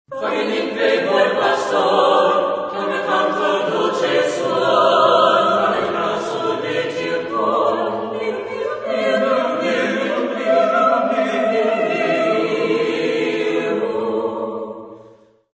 Genre-Style-Form: Secular ; ballet ; Renaissance
Mood of the piece: narrative
Type of Choir: SSATB  (5 mixed voices )
Tonality: A minor